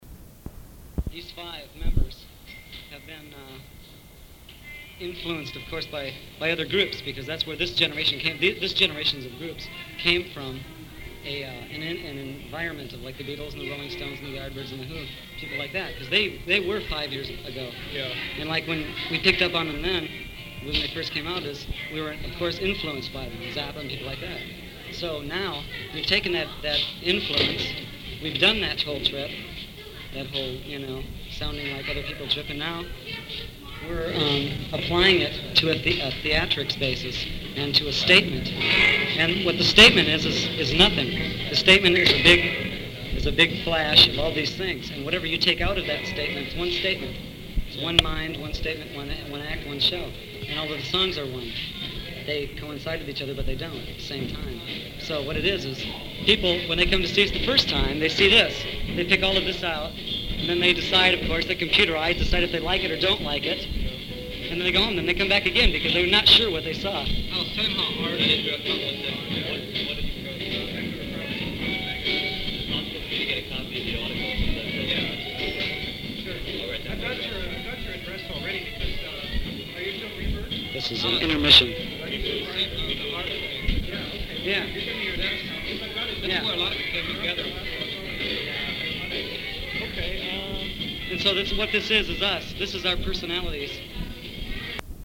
Interview with Alice Cooper
This interview with Alice Cooper was conducted during the festival in a cafeteria-like building where the performers hung out.
brief fragment of this interview has survived (the sound quality is not very good).